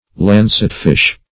lancetfish \lan"cet*fish`\, lancet fish \lan"cet fish`\n.